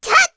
Lilia-Vox_Attack1_kr.wav